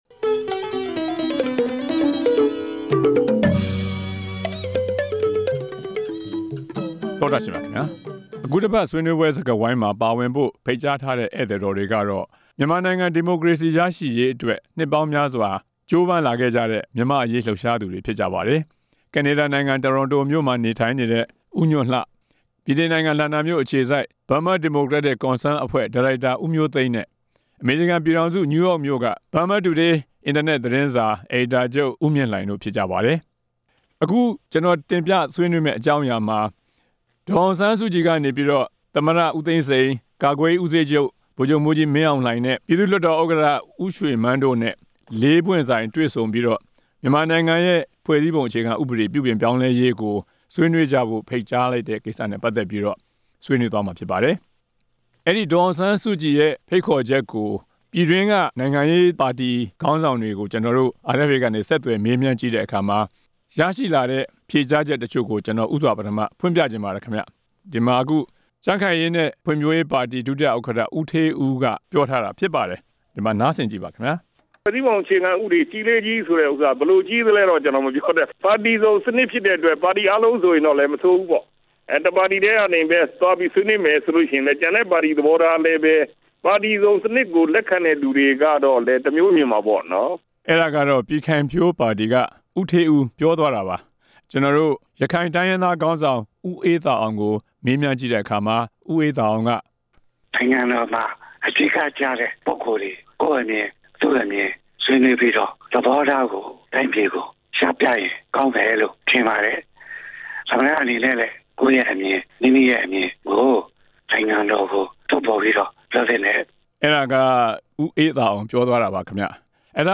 ဆွေးနွေးပွဲစကားဝိုင်း